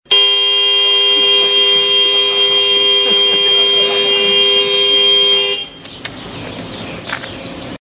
LOUD HORN BLARING.mp3
Original creative-commons licensed sounds for DJ's and music producers, recorded with high quality studio microphones.
loud_horn_blaring_1eo.ogg